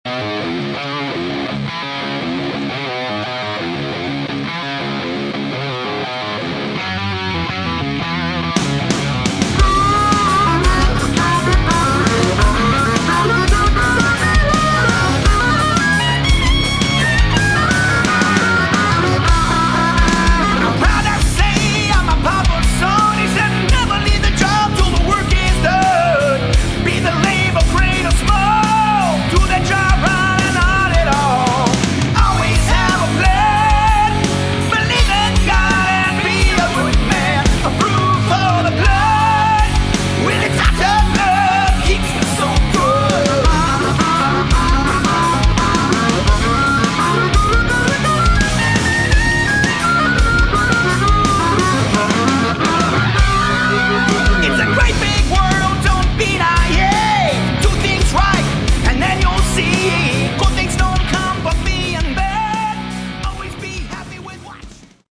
Category: Hard Rock
Lead Vocals
Lead Guitar, Vocals
Drums
Bass Guitar
Good hard rock with an excellent sound.